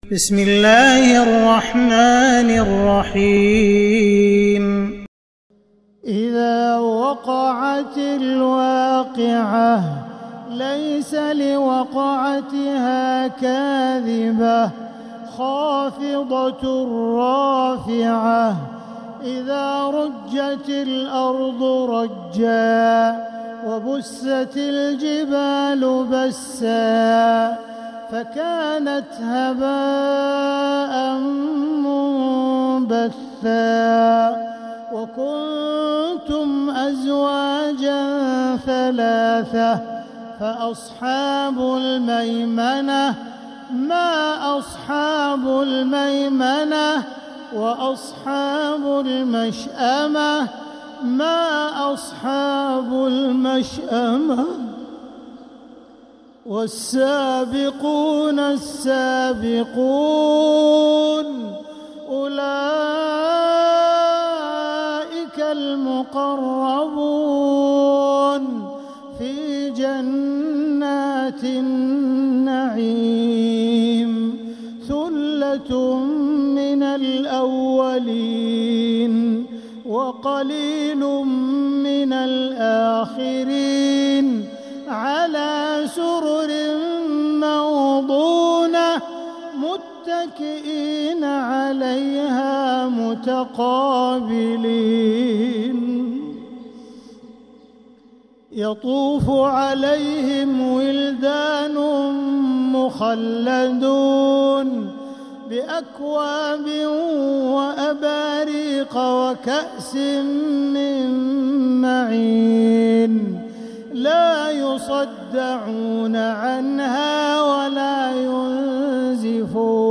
سورة الواقعة كاملة للشيخ عبدالرحمن السديس | فجريات شهر جمادى الآخرة 1447هـ > السور المكتملة للشيخ عبدالرحمن السديس من الحرم المكي 🕋 > السور المكتملة 🕋 > المزيد - تلاوات الحرمين